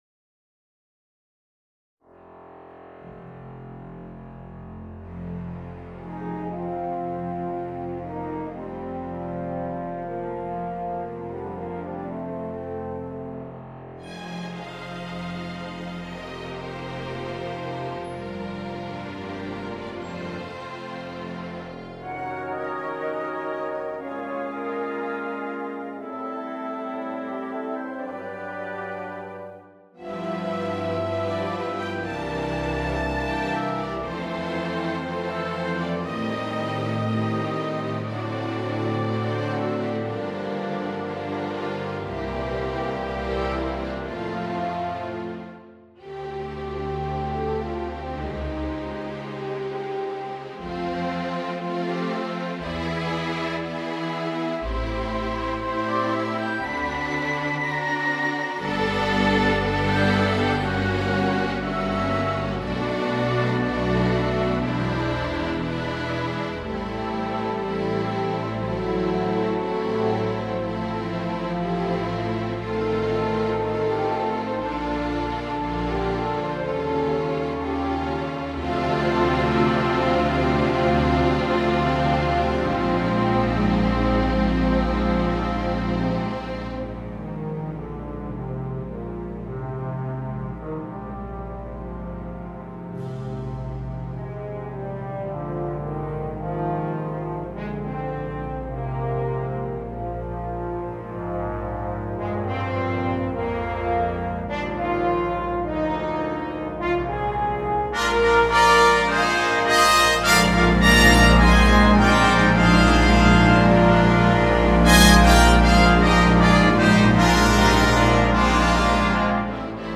Alphorn in F & Orchestra
Sinfonieorchester PDF